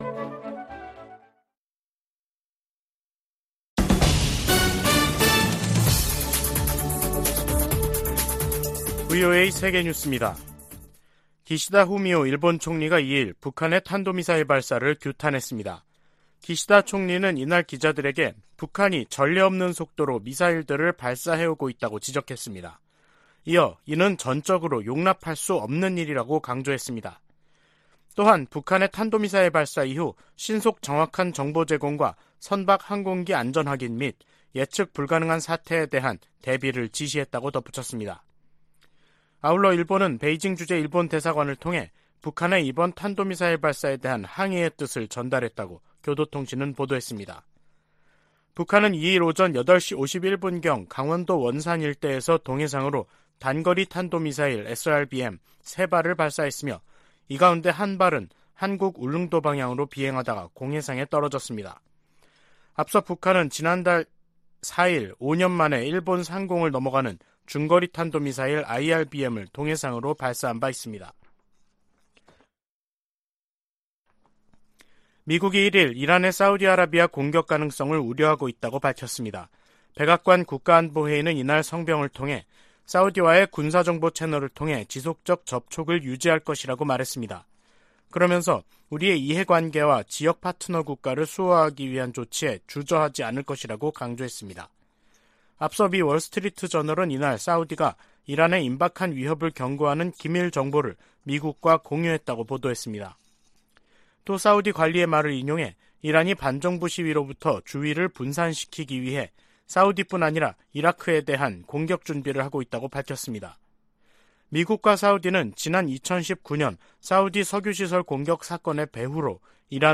VOA 한국어 간판 뉴스 프로그램 '뉴스 투데이', 2022년 11월 2일 2부 방송입니다. 북한 정권이 남북 분단 이후 처음으로 동해 북방한계선 NLL 이남 한국 영해 근처로 탄도미사일을 발사하는 등 미사일 수십 발과 포병 사격 도발을 감행했습니다.